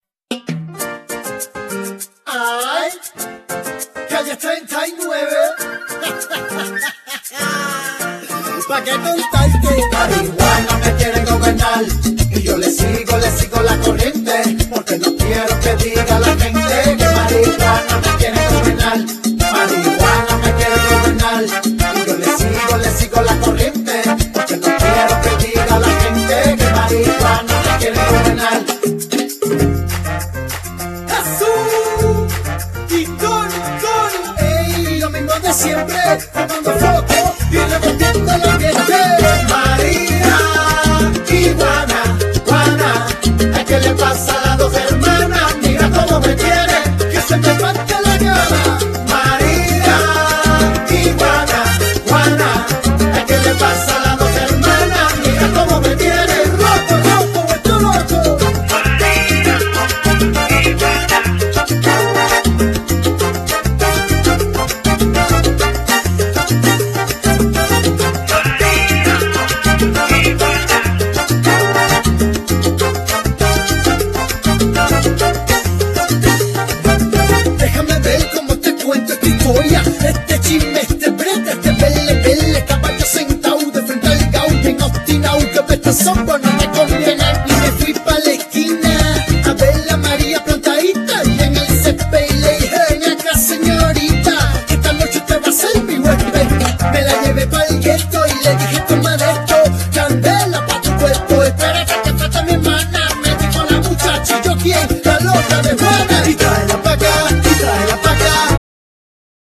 Genere : Latin